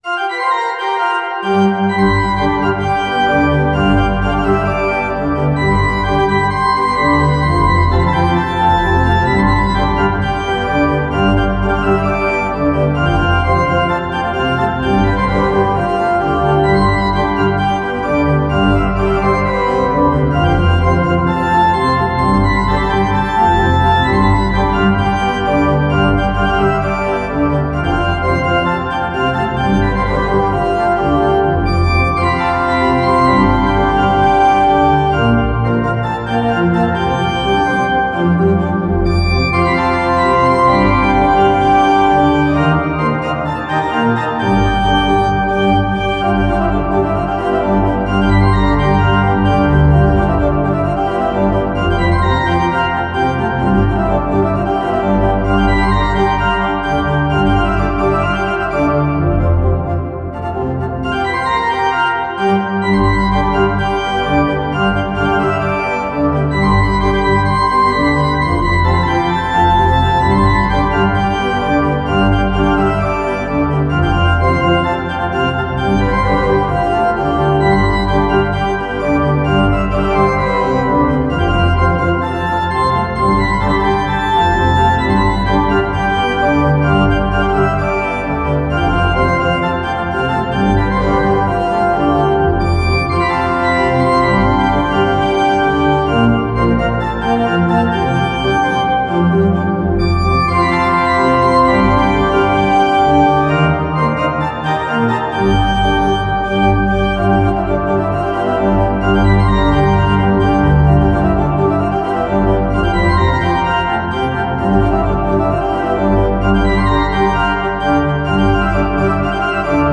pipe_organ